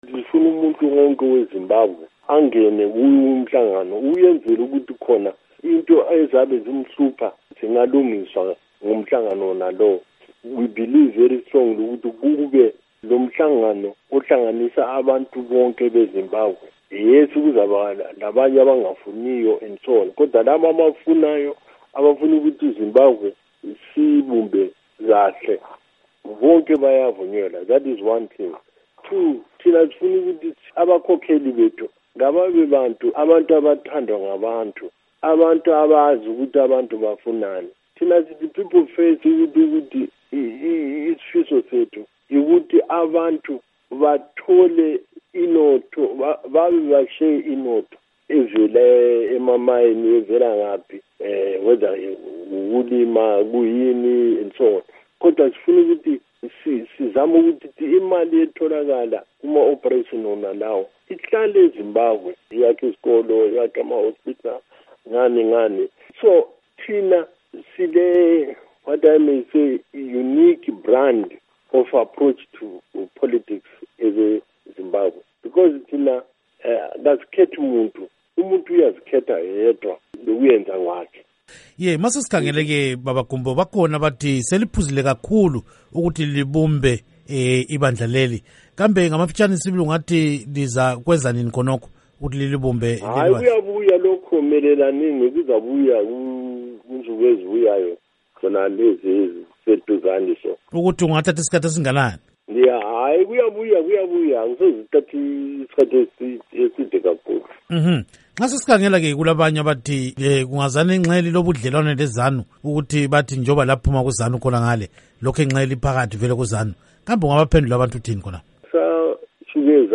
Ingxoxo Esiyenze LoMnu. Rugare Gumbo wePeople First